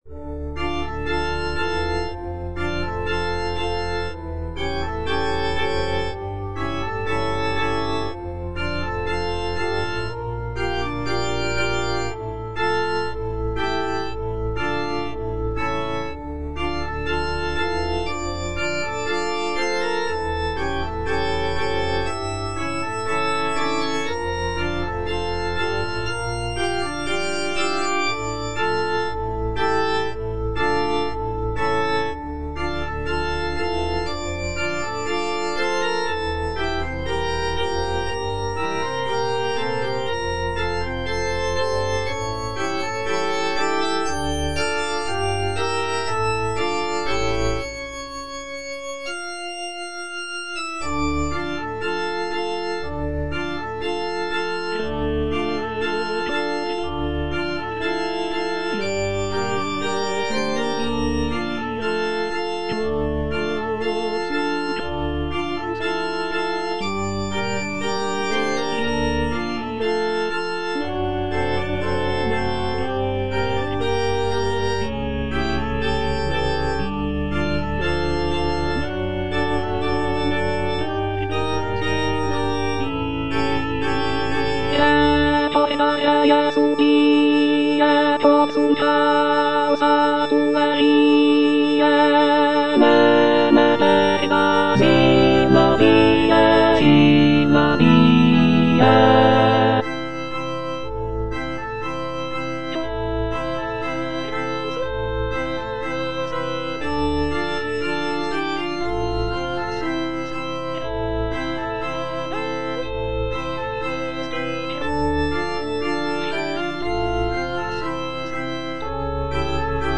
F. VON SUPPÈ - MISSA PRO DEFUNCTIS/REQUIEM Recordare - Alto (Emphasised voice and other voices) Ads stop: auto-stop